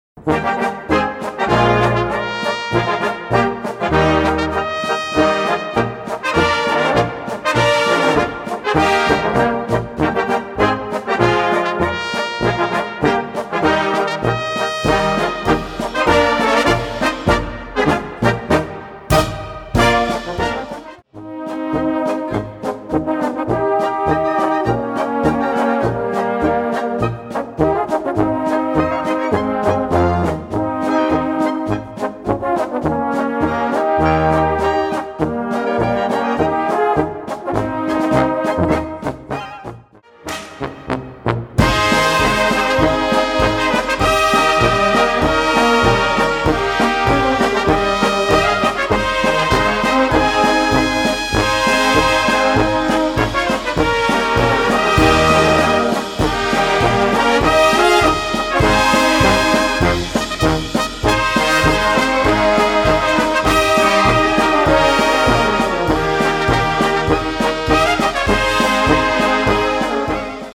Genre: Polka